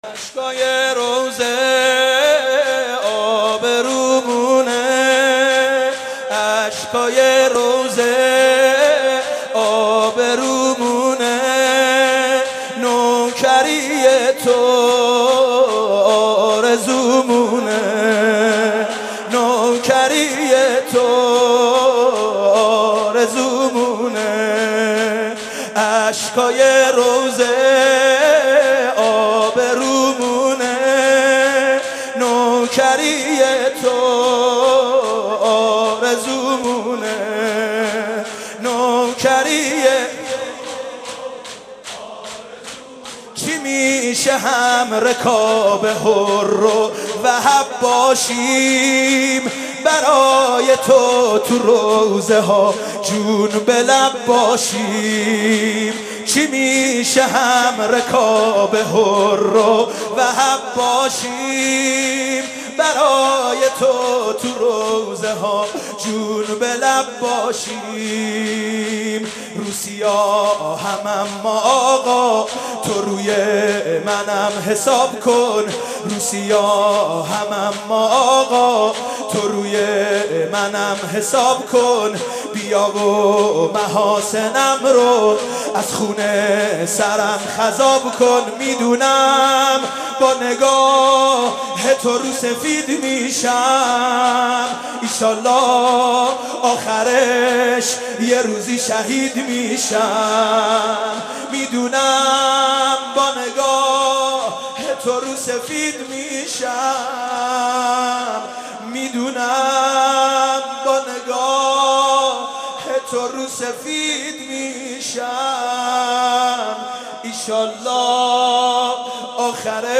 مداحی
maddahi-213.mp3